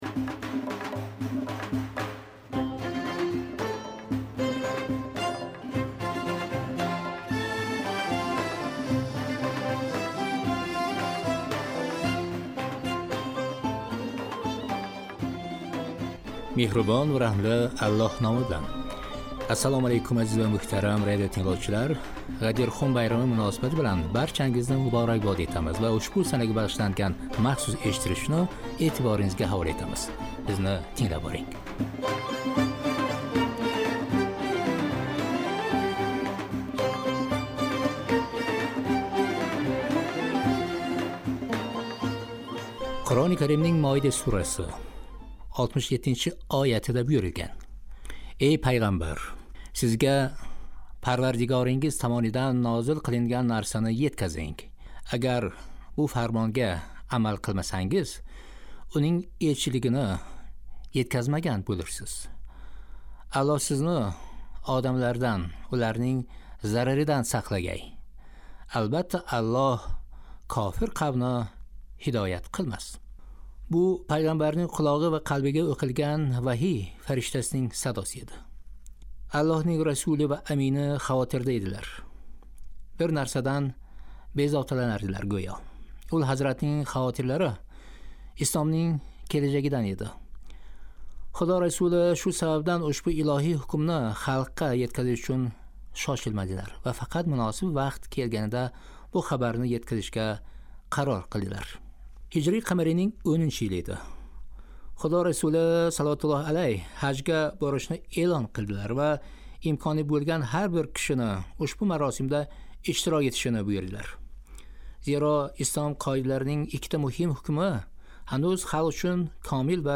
"Ғадир" байрамига бағишланган махсус эшиттириш